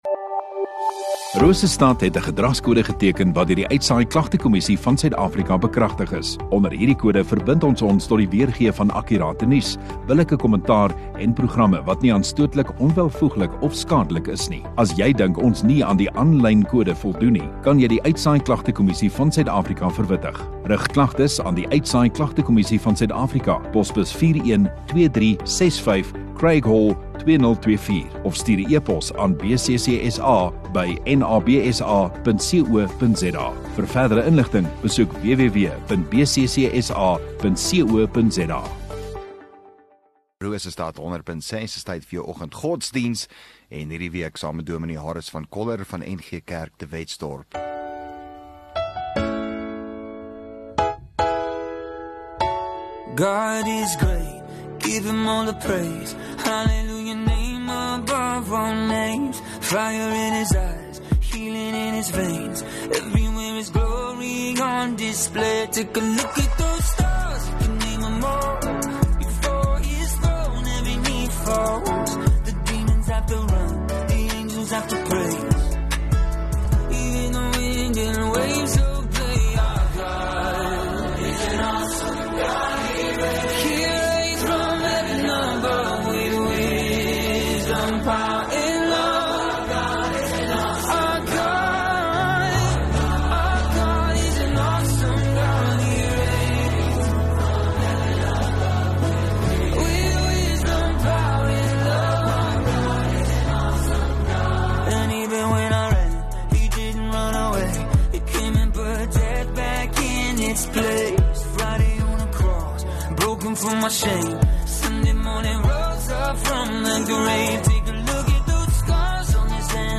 1 Jul Dinsdag Oggenddiens